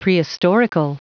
Prononciation du mot : prehistorical
prehistorical.wav